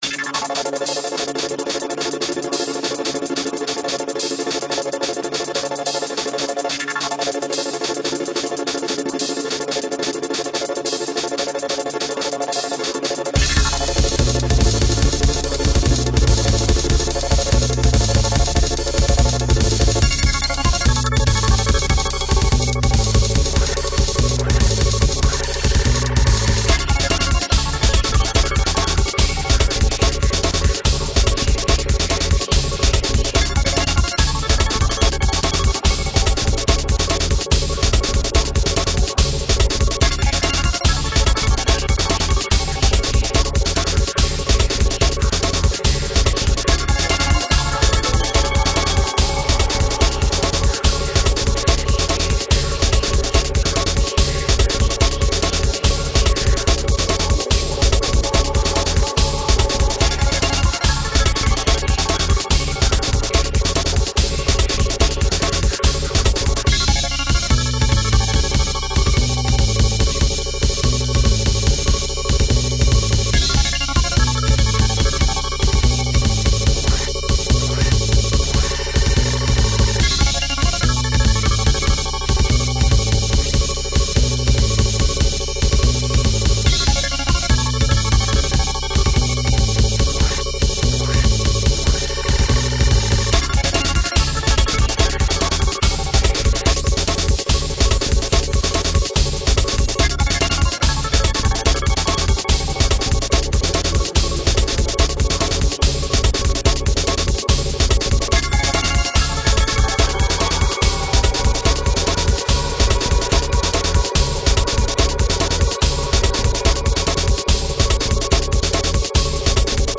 Low fi version (1.8MB) - Airbreaks - Uses granular synthesis combined with a solid kick drum sound.